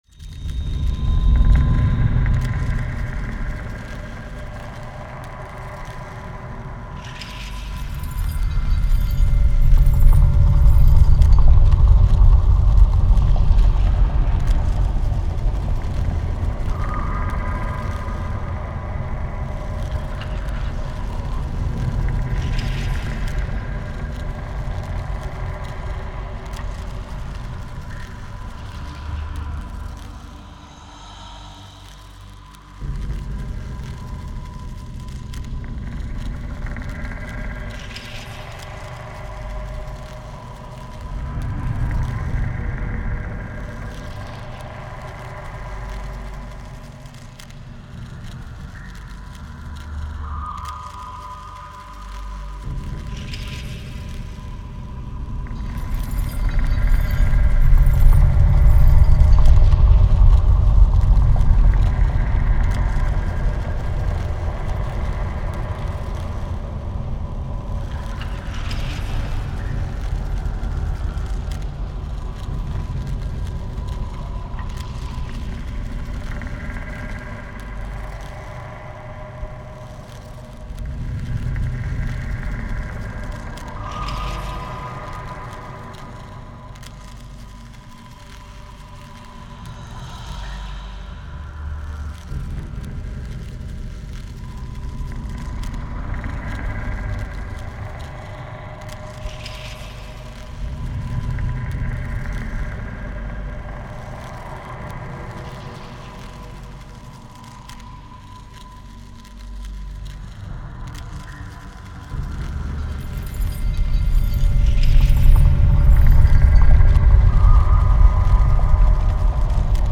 Forgoten tomb ambience
dark
chains
creepy
yeah, there are many types of insects walking there :D, rats, etc , quite busy, intention of this ambience was just to spice up environment, the volume somewhere like 10%, the focus should be on the character and not on the ambience.